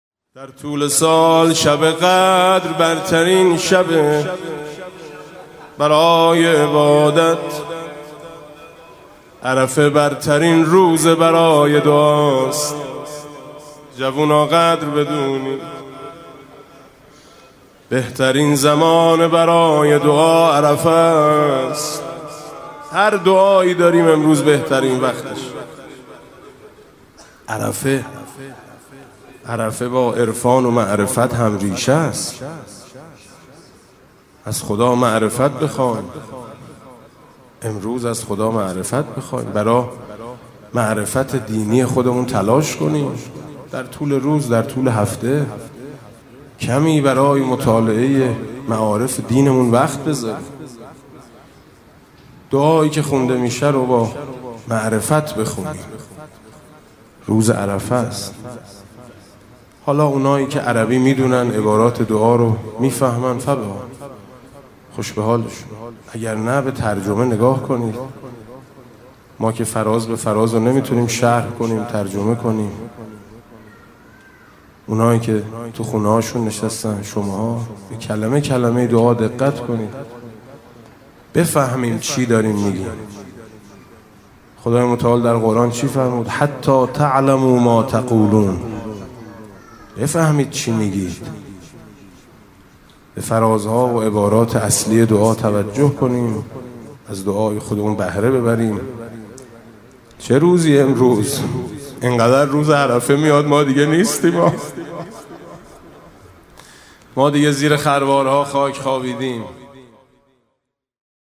[آستان مقدس امامزاده قاضي الصابر (ع)]
مناسبت: قرائت دعای عرفه